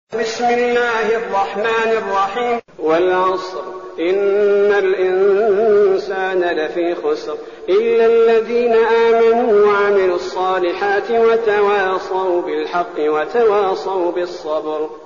المكان: المسجد النبوي الشيخ: فضيلة الشيخ عبدالباري الثبيتي فضيلة الشيخ عبدالباري الثبيتي العصر